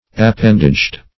\Ap*pend"aged\